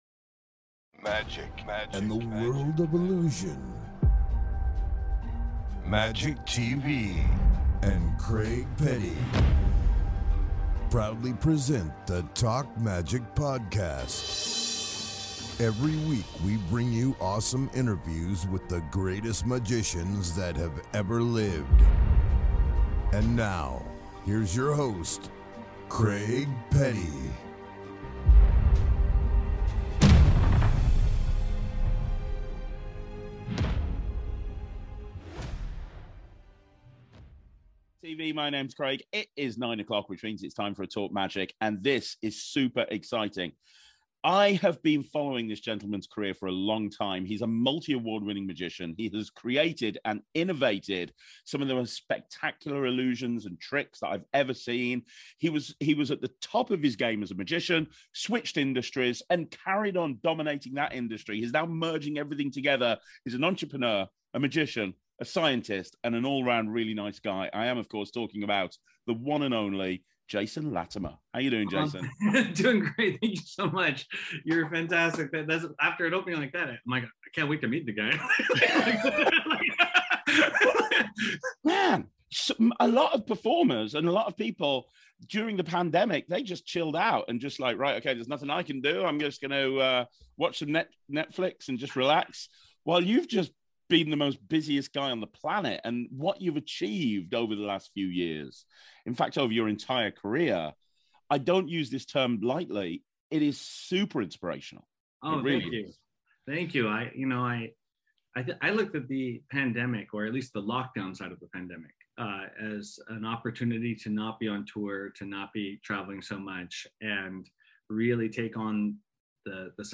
This interview is very inspirational and really should be a must watch.